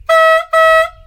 MR tyfon (direkte lydlink)
Lyden af den "lille" tyfon fra MR motorvognen.
MR_tyfon.mp3